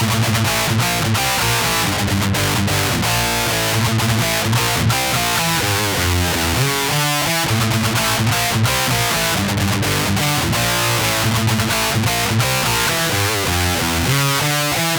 Попробовал интереса ради демку скачать, там доступен тредплейт, сравнил с DI профайлом ректы который сам снимал. Честно говоря странно звучит, отключил всю постобработку (ну и пре, все педали), примерно воспроизвел настройки с которыми профайл снимал.